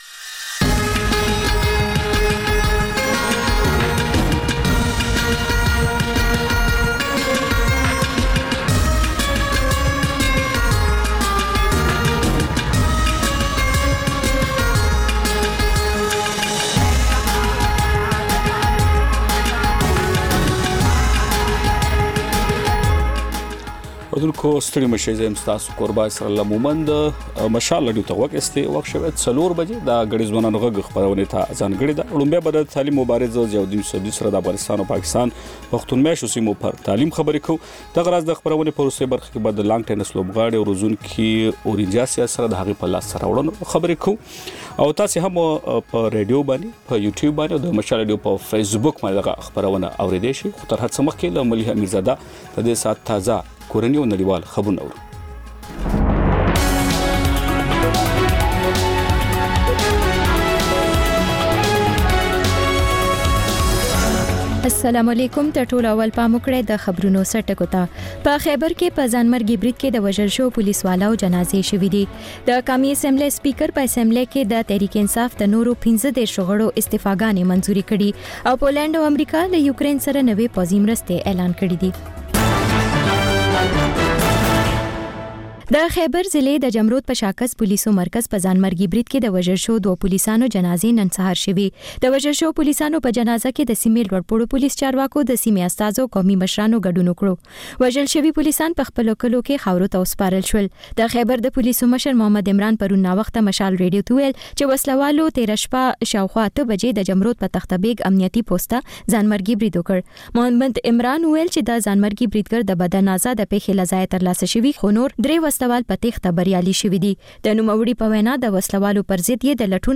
د مشال راډیو مازیګرنۍ خپرونه. د خپرونې پیل له خبرونو کېږي، بیا ورپسې رپورټونه خپرېږي.